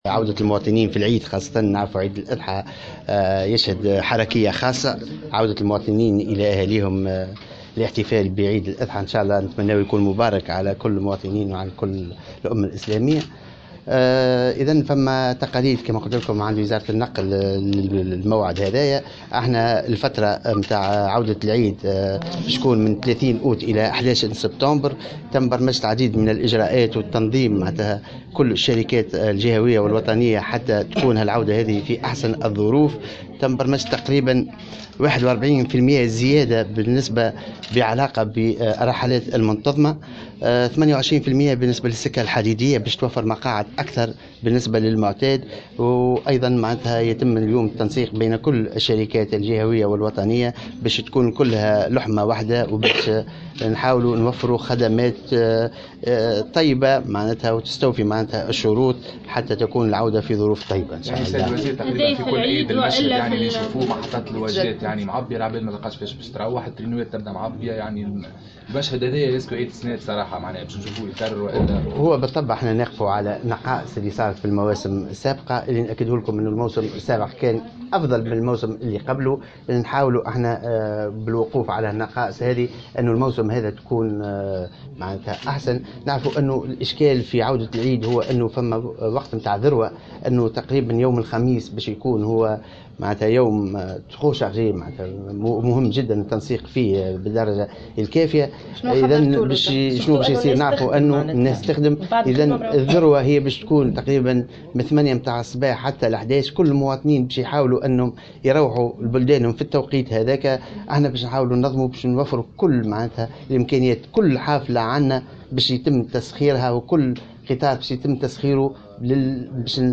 قال وزير النقل أنيس غديرة في تصريح لمراسلة الجوهرة "اف ام" عقب اجتماعه صباح اليوم بالرؤساء المديرين العامين للشركات الجهوية و الوطنية للنقل إن استعدادات الوزارة بمناسبة عيد الاضحى جارية على أحسن وجه و إنه تم اتخاذ عديد اللاجراءات في هذا الخصوص في الفترة الممتدة من 30 أوت الى 11 سبتمبر 2017.